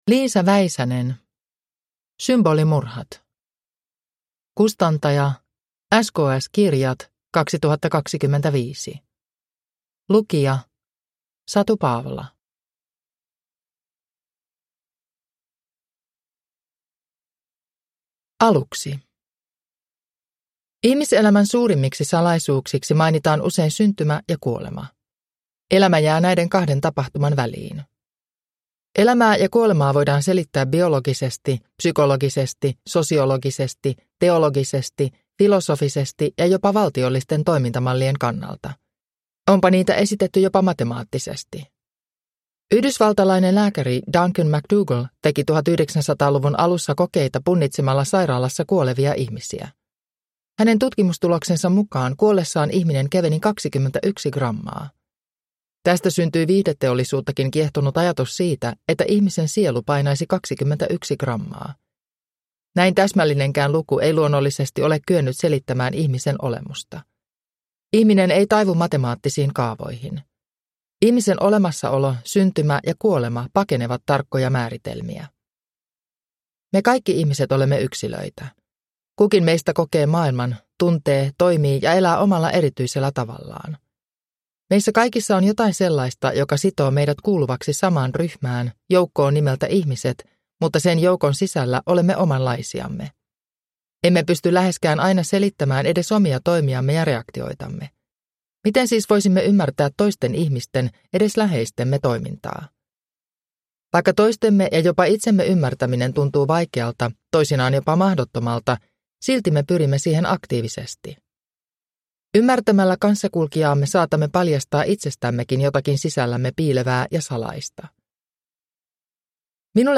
Symbolimurhat – Ljudbok